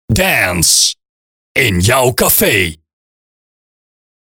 Zonder Soundeffects
02 - Dance - In jouw cafe (Voice Only).mp3